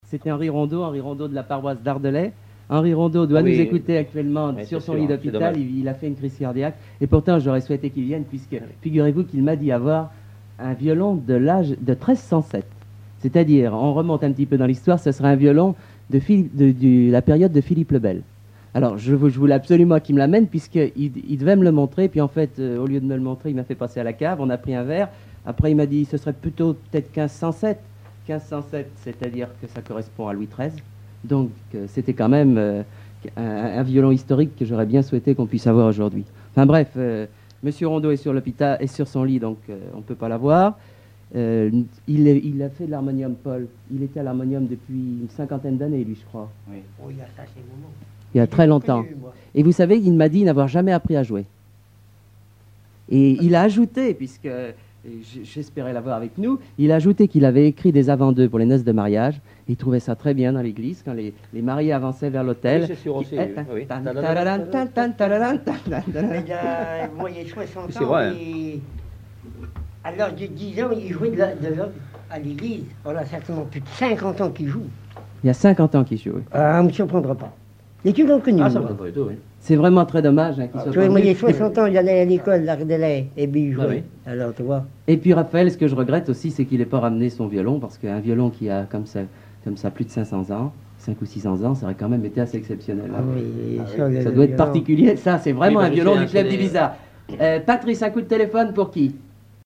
émission d'Alouette FM
Témoignage